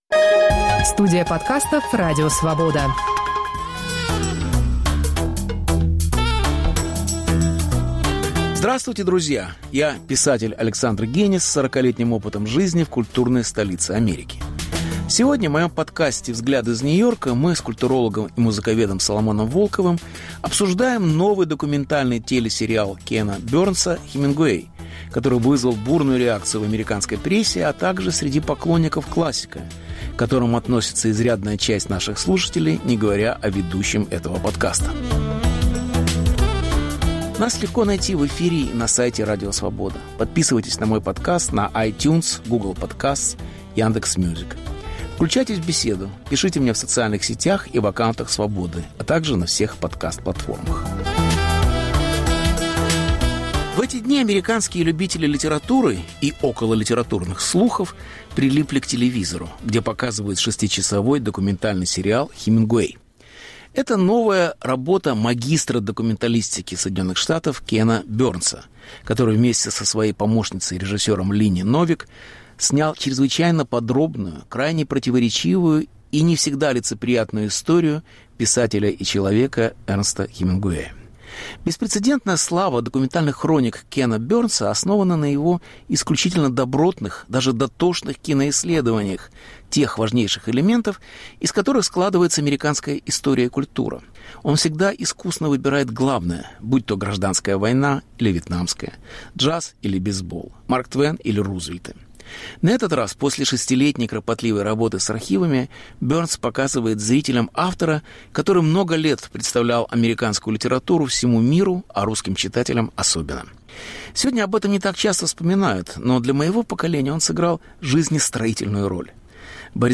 Беседа с Соломоном Волковым о сериале Кена Барнса. Повтор эфира от 19 апреля 2021года.